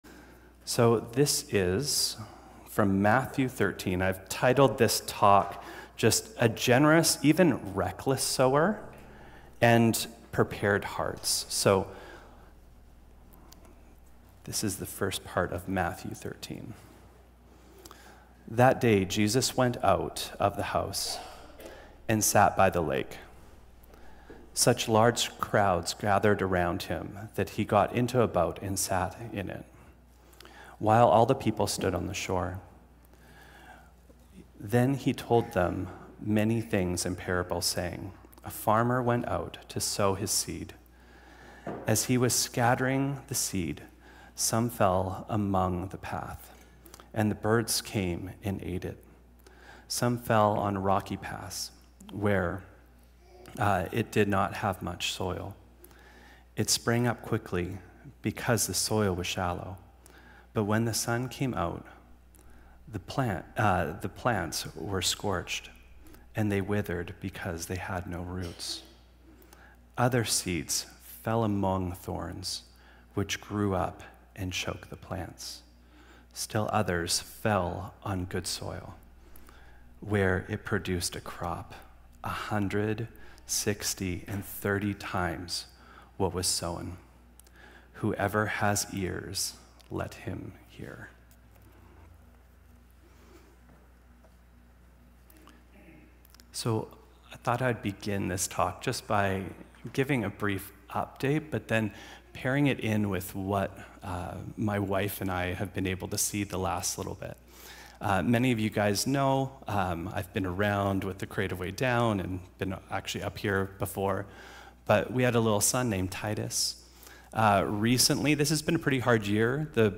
Cascades Church Sermons A Generous Sower & A Prepared Heart Play Episode Pause Episode Mute/Unmute Episode Rewind 10 Seconds 1x Fast Forward 30 seconds 00:00 / 33:55 Subscribe Share Apple Podcasts RSS Feed Share Link Embed